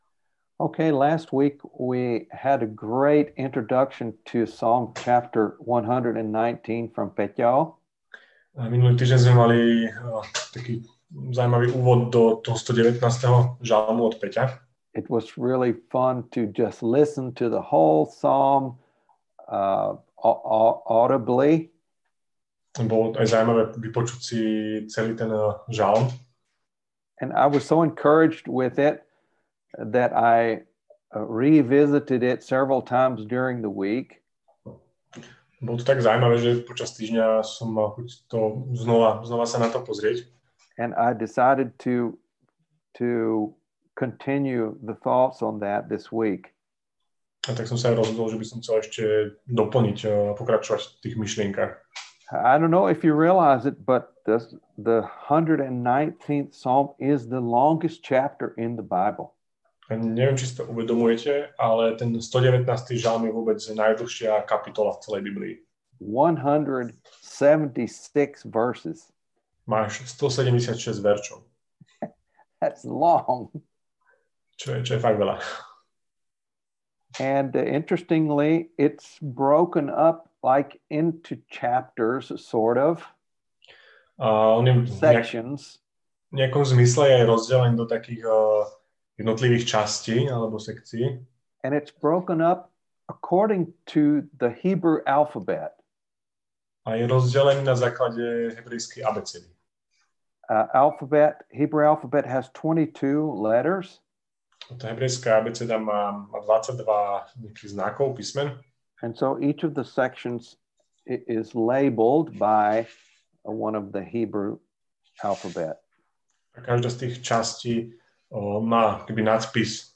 Nahrávka kázne Kresťanského centra Nový začiatok z 9. februára 2021